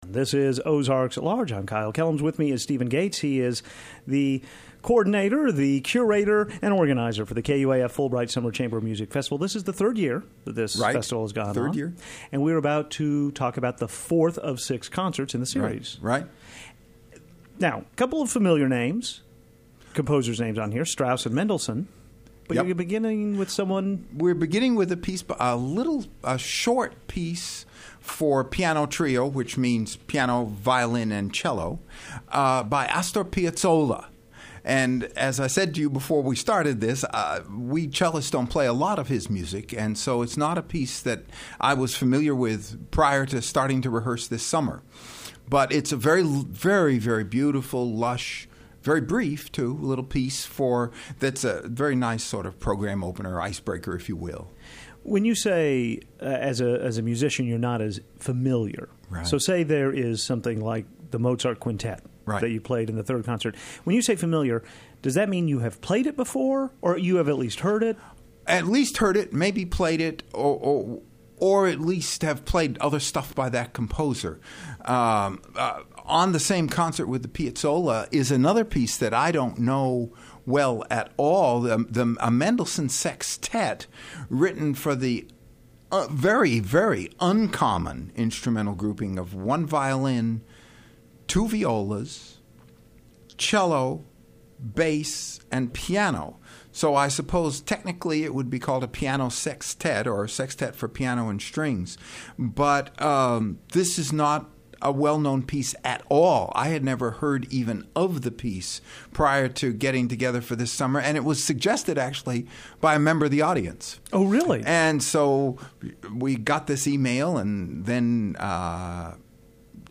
Piazzolla, Strauss and Mendelssohn are featured in the fourth KUAF/Fulbright Chamber Music Festival.
Chamber_Concert_4.mp3